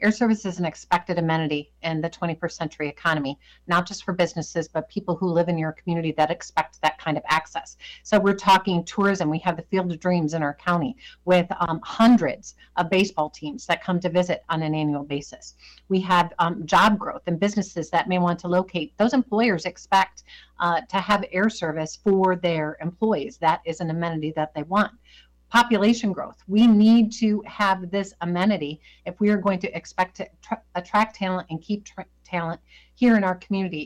Fly DBQ Holds Virtual Town Hall Meeting